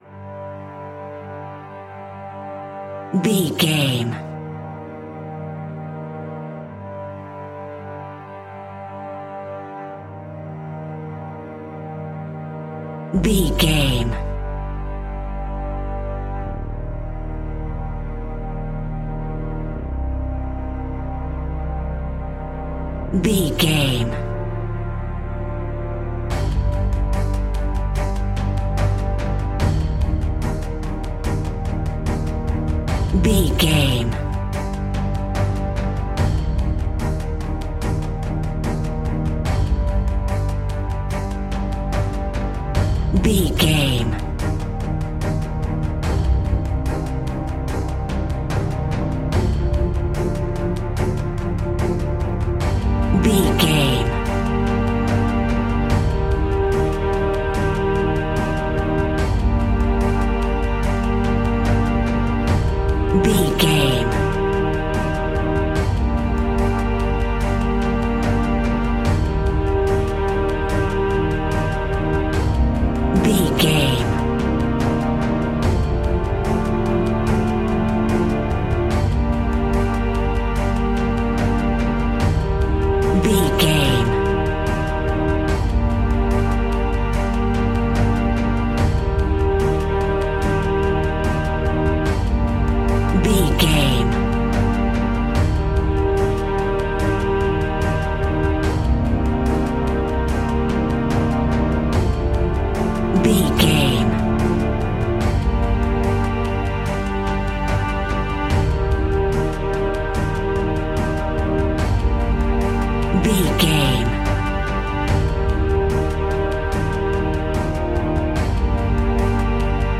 Epic / Action
Fast paced
In-crescendo
Uplifting
Aeolian/Minor
strings
brass
percussion
synthesiser